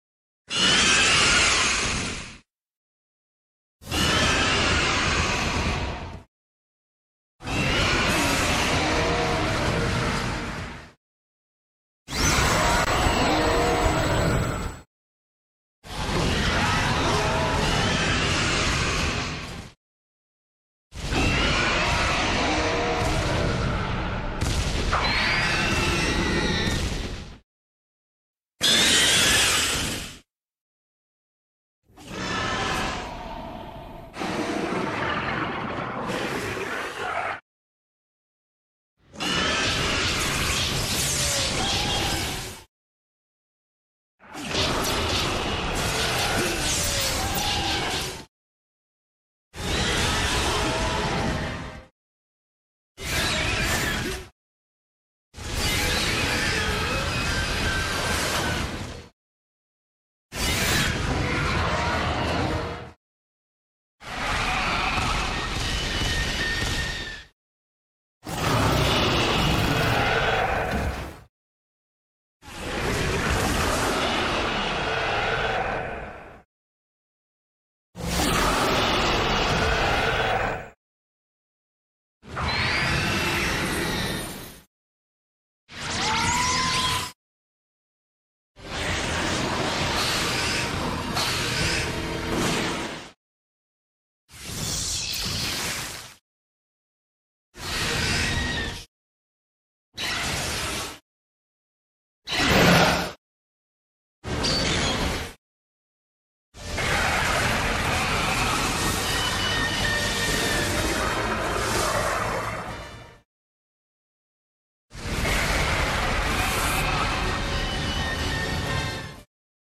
Izmael_roars.ogg